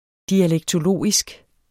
Udtale [ dialεgtoˈloˀisg ]